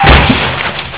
The two sounds in the parenthesis are fire button sounds.
Sound Effects (demo only, actual recording is longer)
Crash)
crash.wav